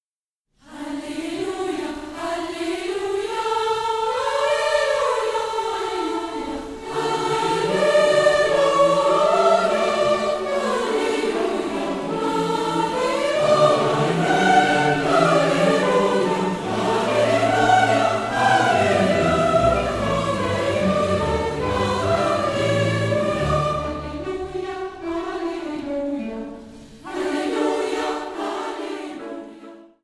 • kurzweilige Zusammenstellung verschiedener Live-Aufnahmen
Chor, Orchester, Klavier